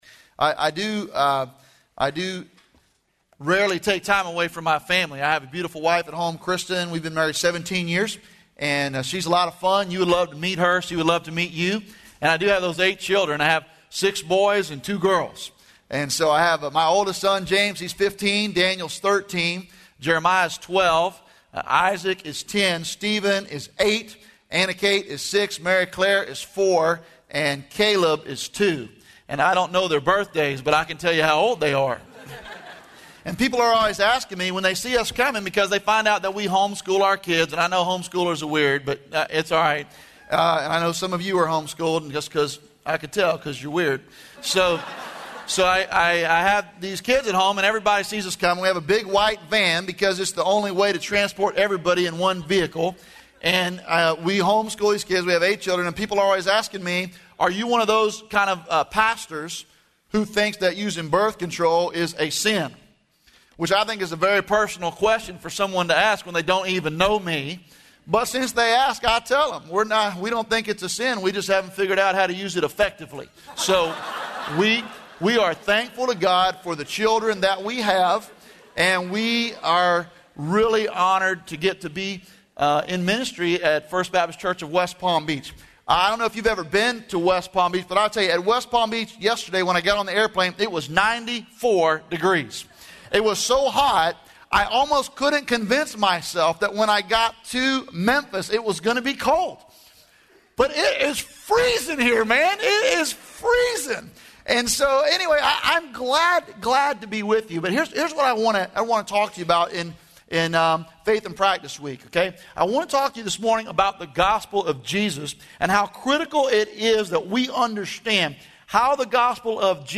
Faith in Practice Chapel